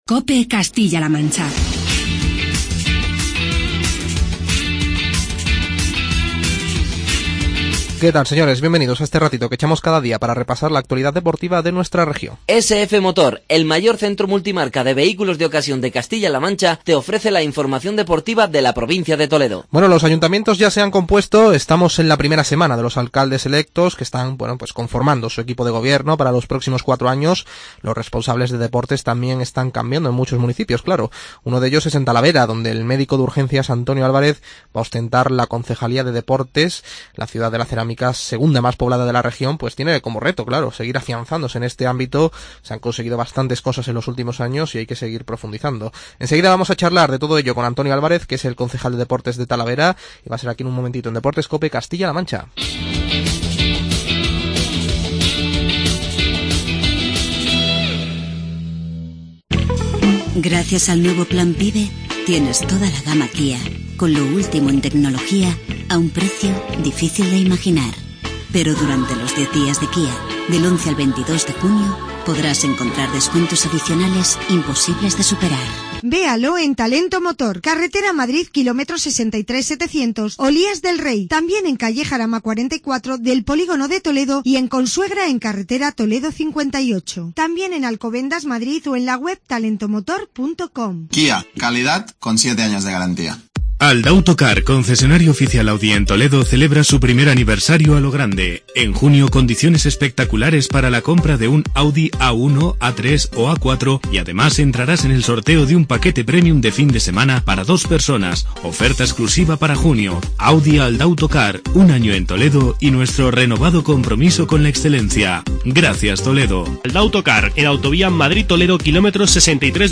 Charlamos con Antonio Álvarez, concejal de Deportes del Ayuntamiento de Talavera